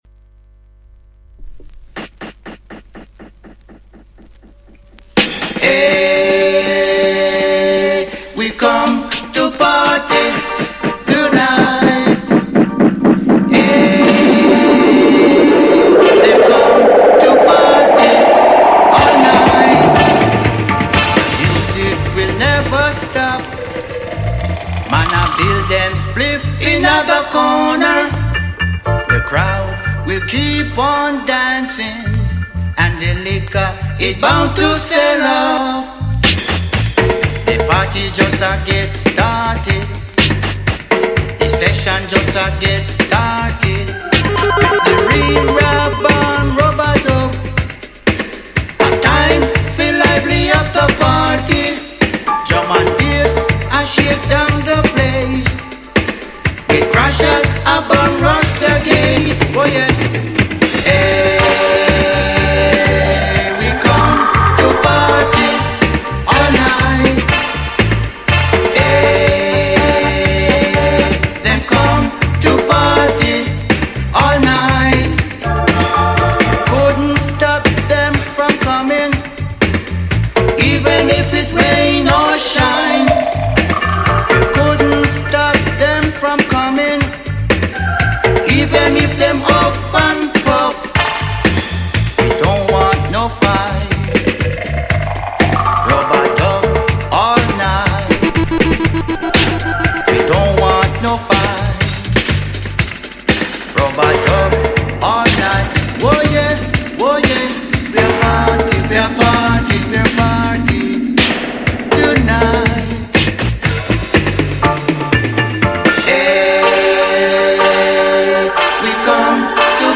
Every week,one hour with reggae music!Only vinyl!Big Up all listeners!